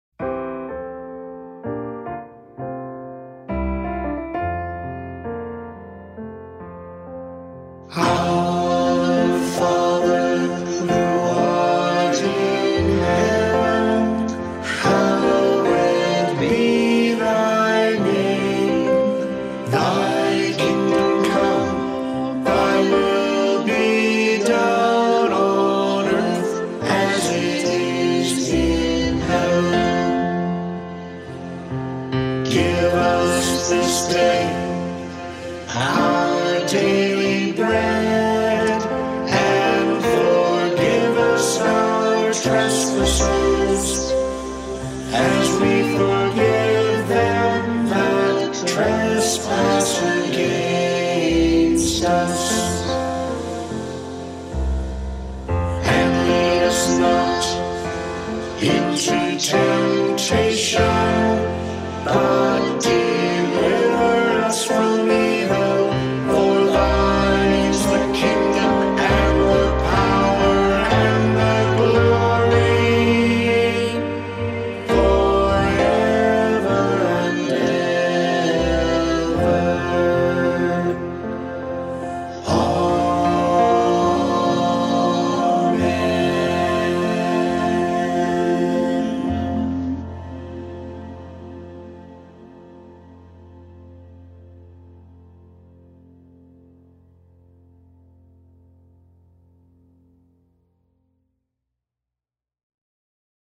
Hymns/Music from Sunday Morning Podcasts, 2020 - One
a digital orchestra
(A couple of notable exceptions when the SPPC Choir joined in. ) But beautiful songs are beautiful songs.